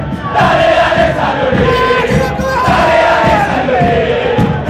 dale san lore Meme Sound Effect